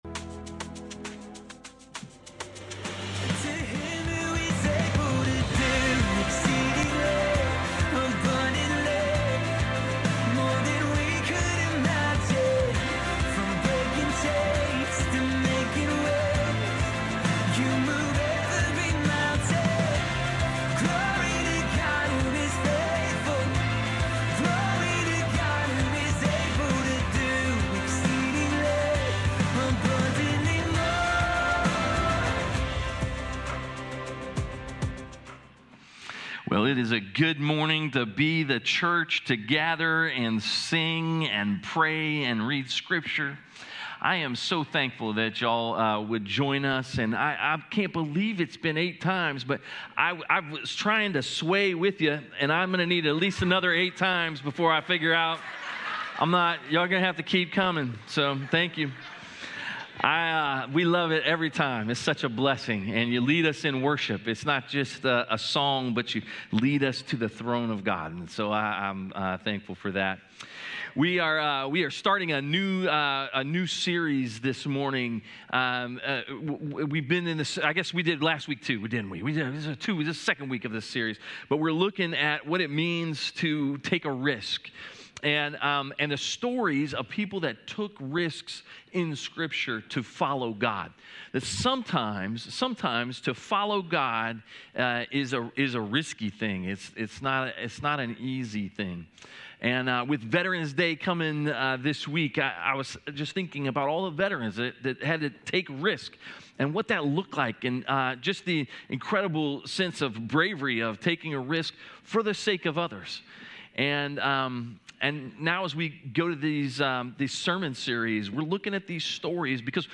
A message from the series "Risky Business."